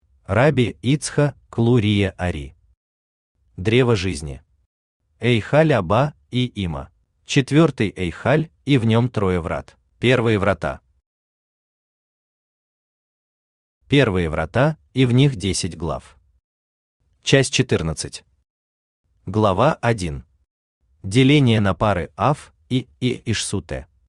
Аудиокнига Древо Жизни. Эйхаль Аба и Има | Библиотека аудиокниг